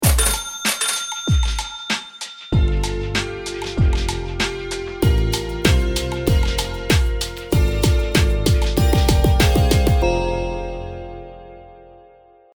I created this 10-second jingle to time auctions in a board game we were playing. Everyone got 10 seconds to bid or pass, and if you get to the end of the jingle you automatically pass.